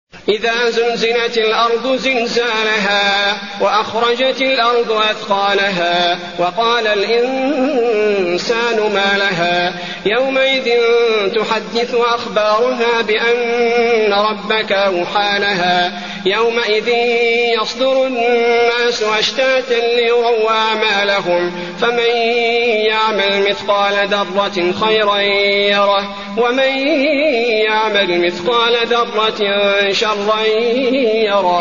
المكان: المسجد النبوي الزلزلة